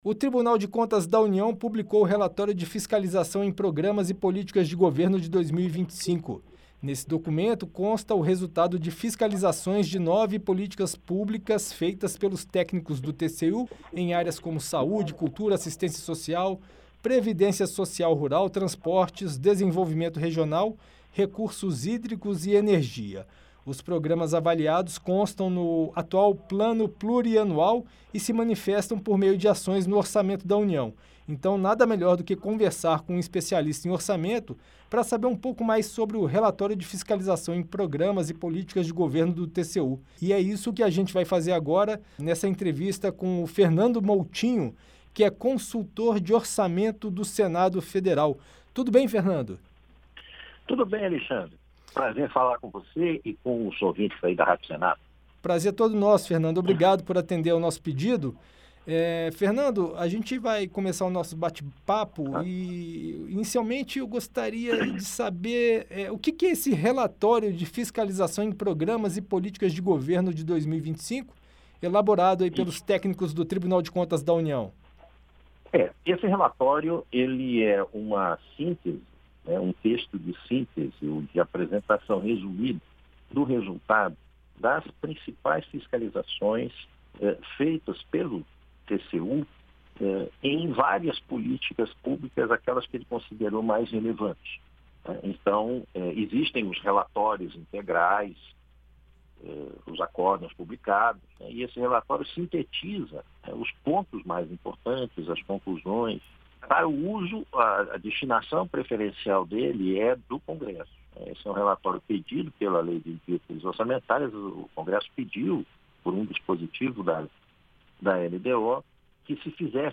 Em entrevista à Rádio Senado